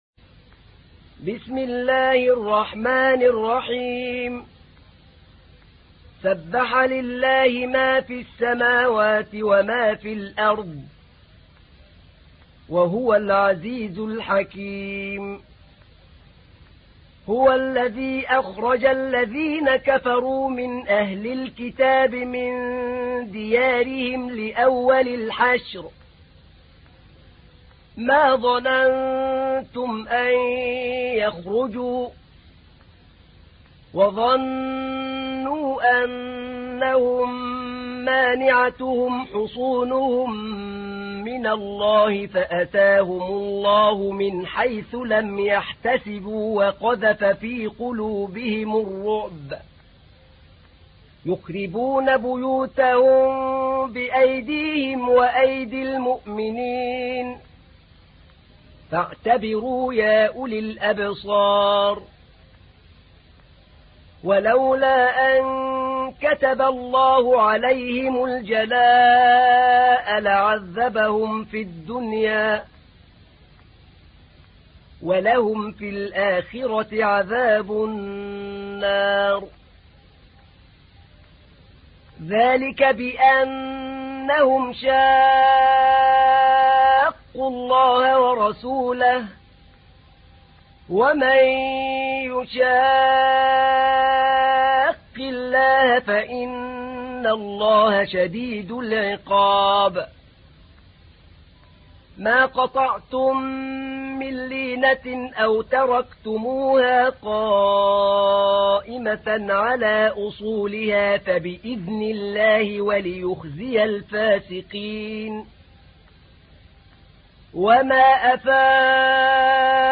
تحميل : 59. سورة الحشر / القارئ أحمد نعينع / القرآن الكريم / موقع يا حسين